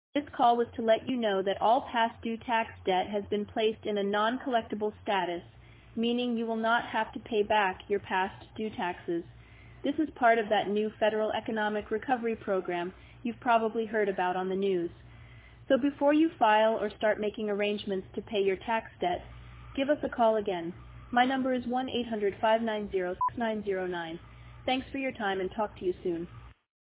Check out this “vish,” a voicemail from a scammer.